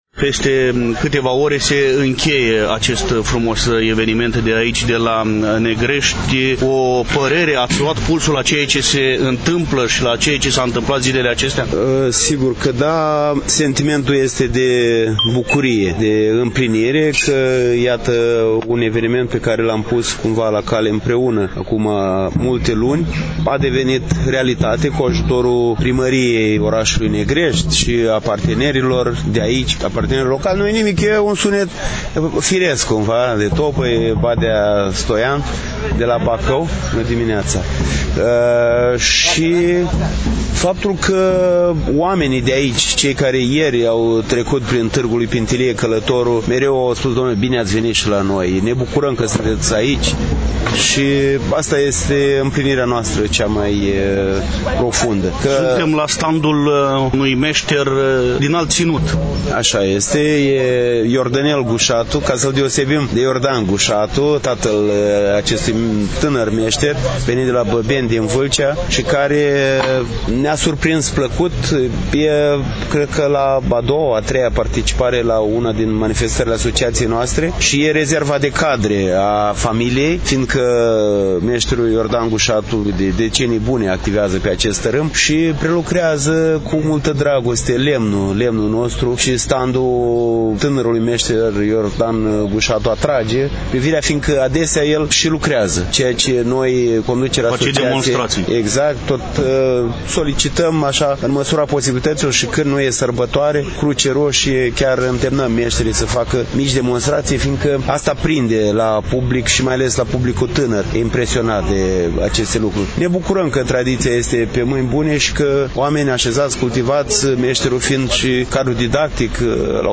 I-am întâlnit, la Negrești, Vaslui, 29-30 iulie 2023, pe Esplanada Casei de Cultură „Neculai Cioată”, la prima ediție a Târgului lui Pintilie călătorul.